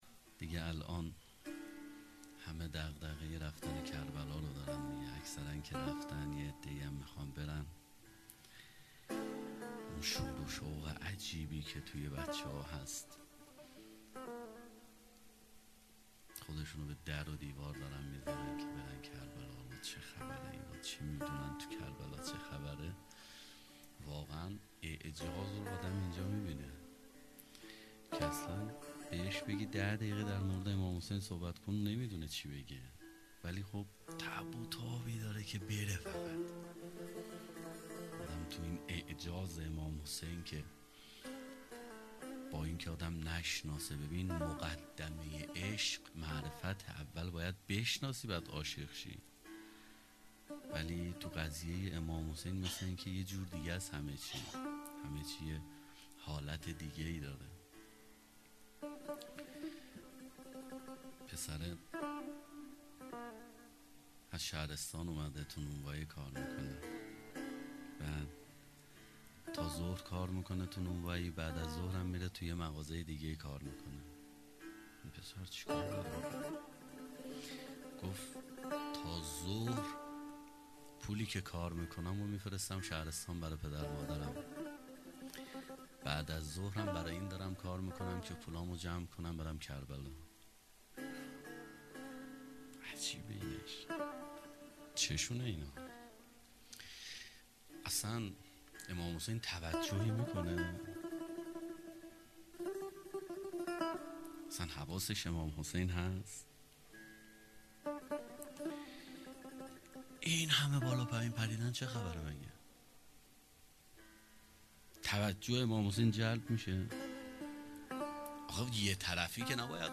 سخنران
بدرقه زائرین اربعین